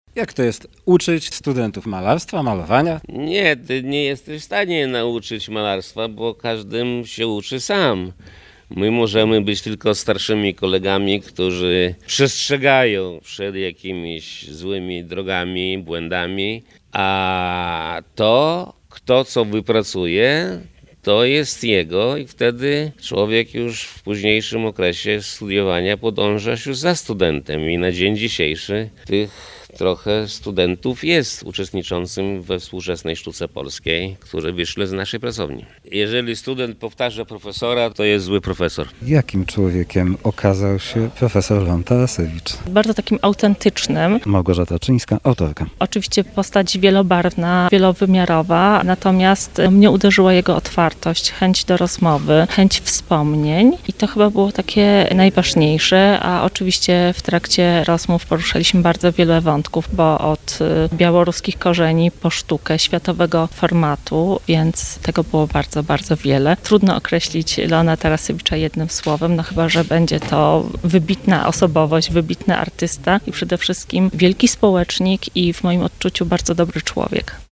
Spotkanie odbyło się w Uniwersyteckim Centrum Kultury w kampusie UwB.